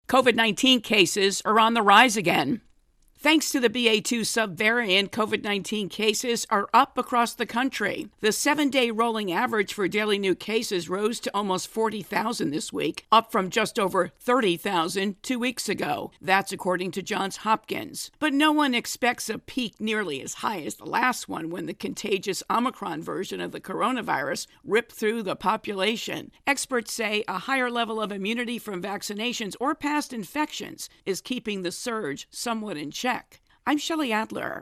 COVID intro and voicer